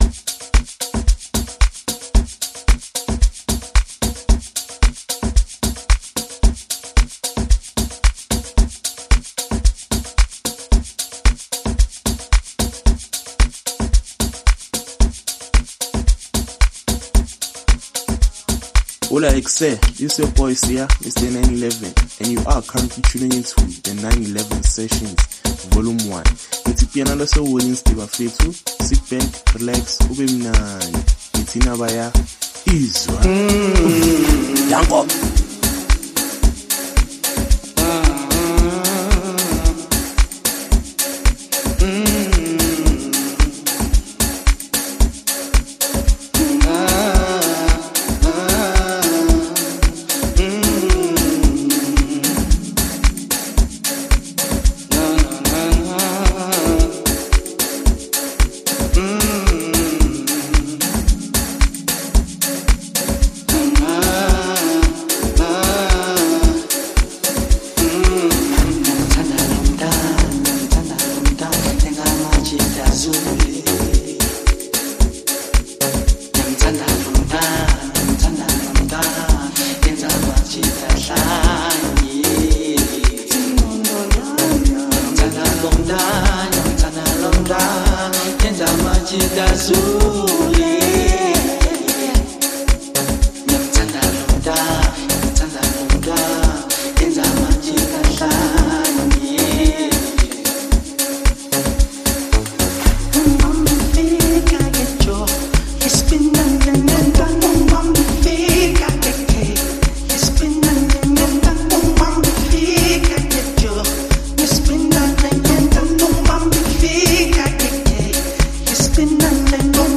Amapiano mixtape series